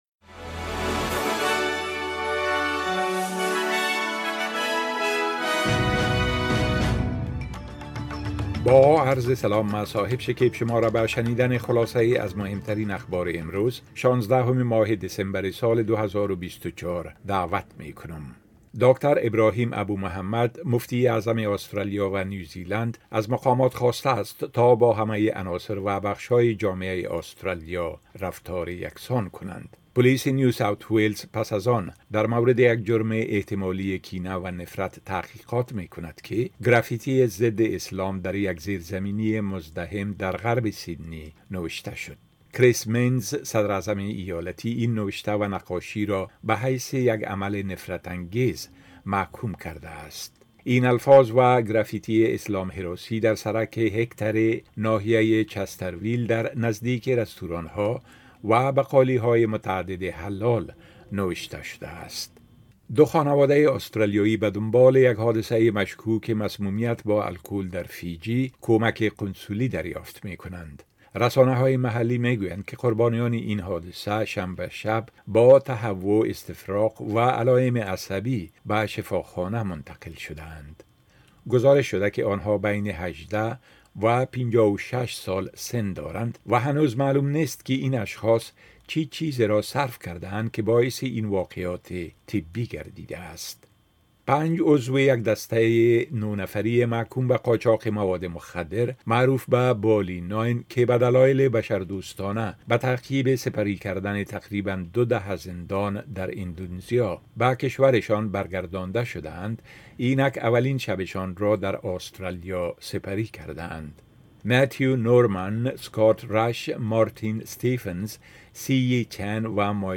10 am News Update Source: SBS / SBS Filipino